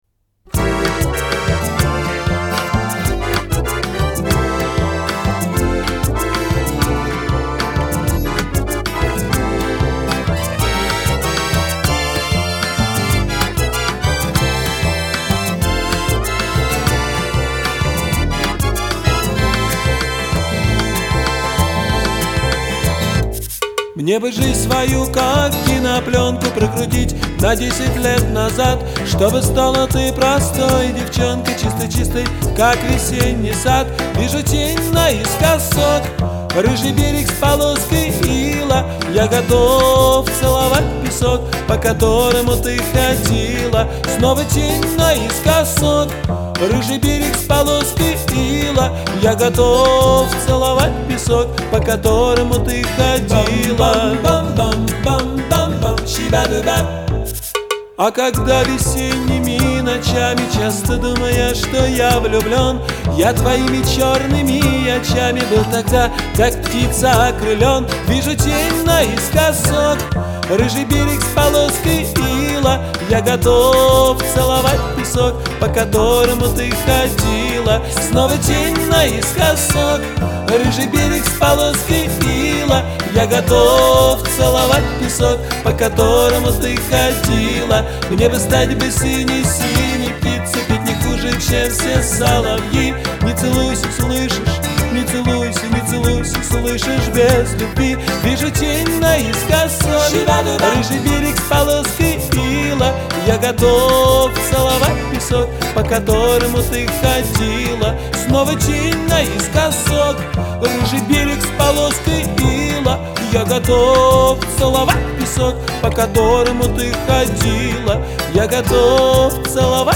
в жанре поп